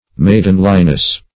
Meaning of maidenliness. maidenliness synonyms, pronunciation, spelling and more from Free Dictionary.
Maidenliness \Maid"en*li*ness\, n.